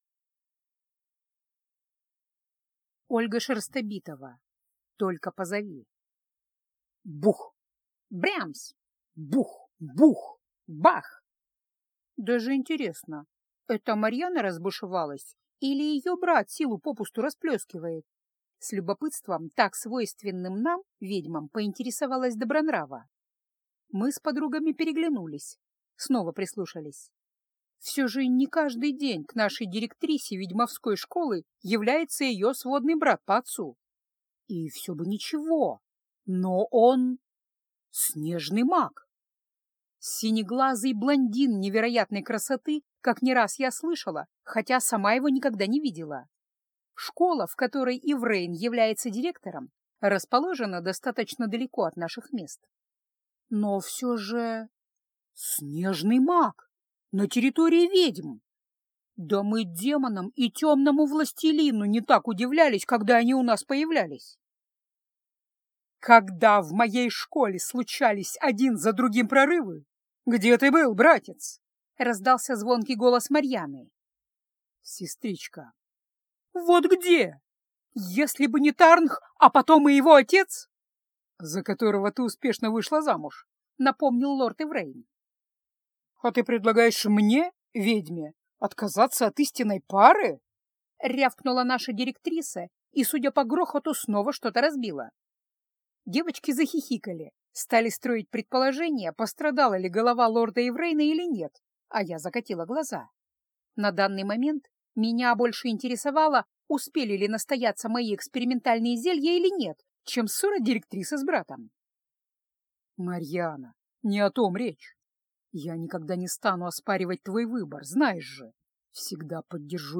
Аудиокнига Только позови | Библиотека аудиокниг
Прослушать и бесплатно скачать фрагмент аудиокниги